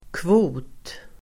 Uttal: [kvo:t]